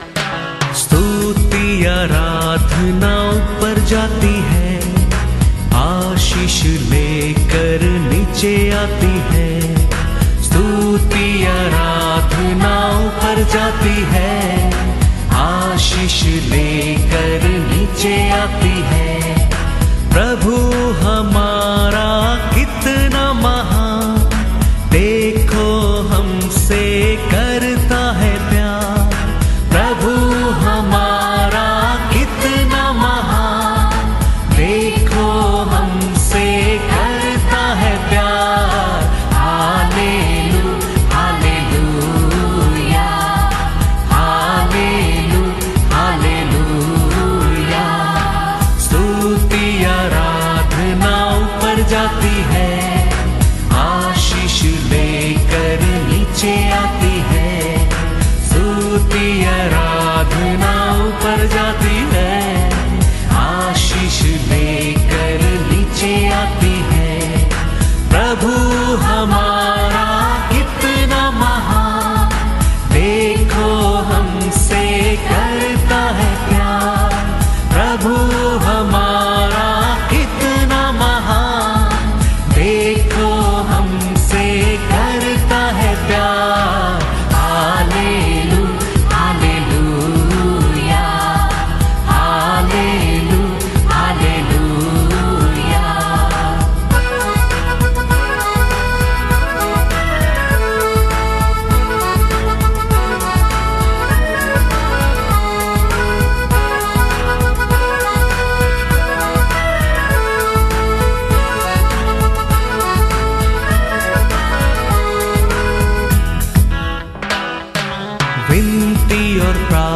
Song Lyrics Christian Song